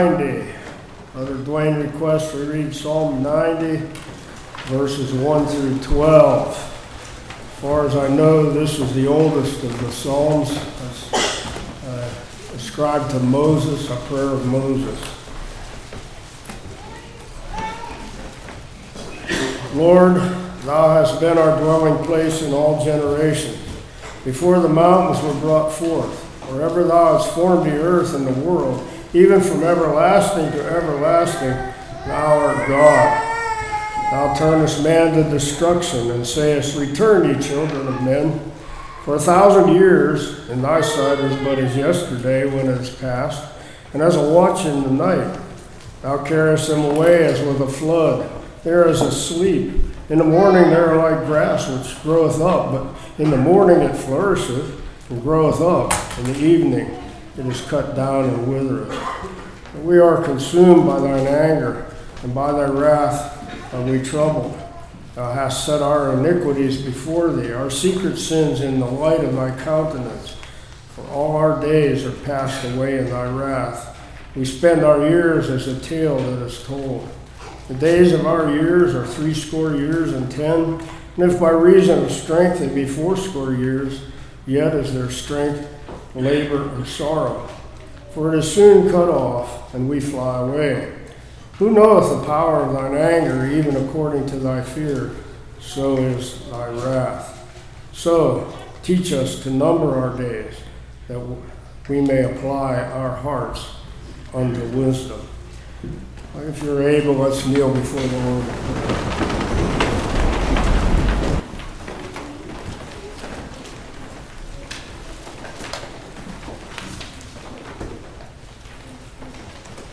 Passage: Psalms 90:1-12 Service Type: Morning The Road We Could Have Taken The Road We Are On False Information Am I Committed to Jesus?